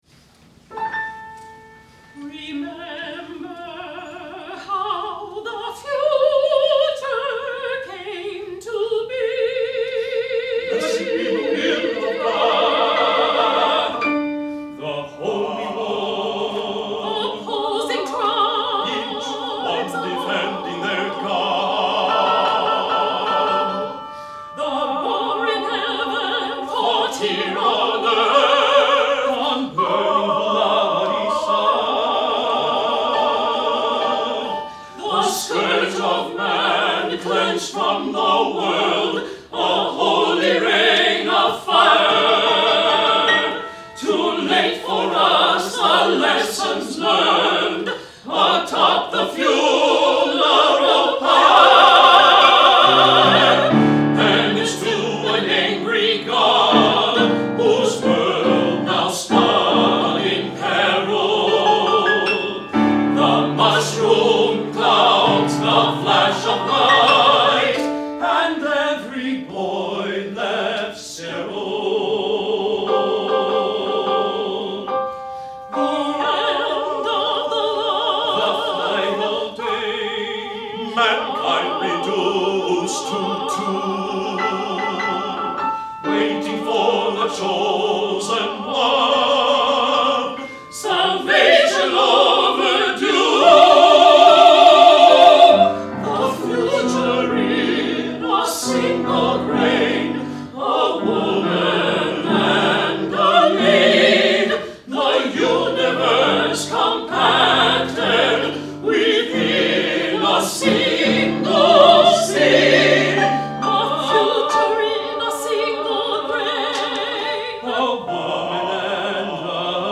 SEED score; a post apocalopera